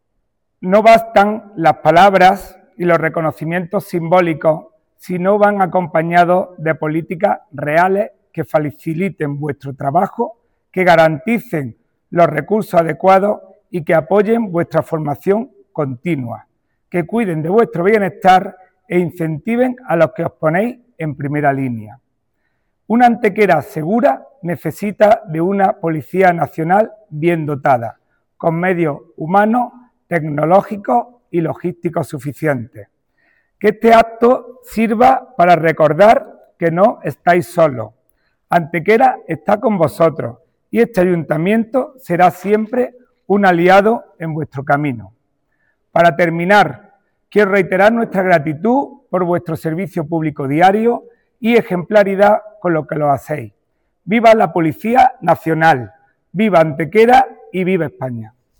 El primer teniente de Alcalde y vicepresidente de la Diputación de Málaga, Juan Rosas, fue el encargado de intervenir en nombre del Ayuntamiento durante el acto conmemorativo de la festividad de los Santos Ángeles Custodios, patronos de la Policía Nacional.
Cortes de voz